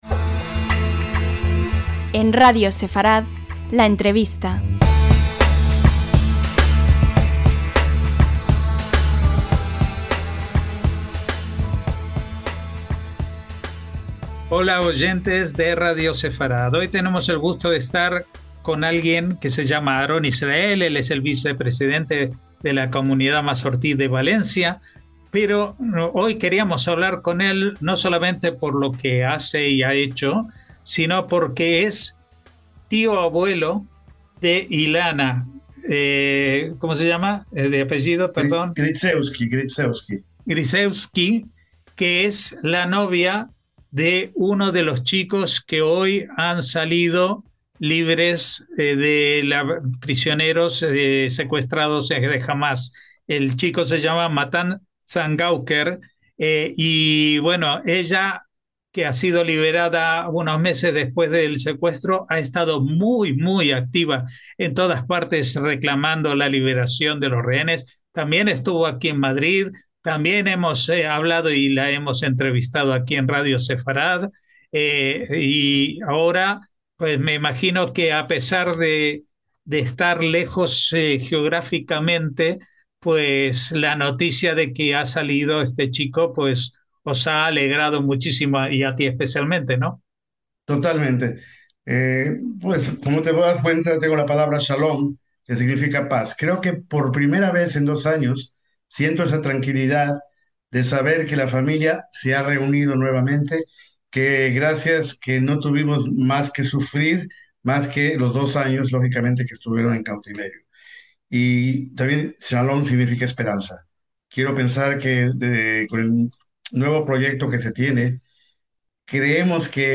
LA ENTREVISTA